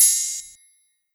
WOD - OPENHAT 2.wav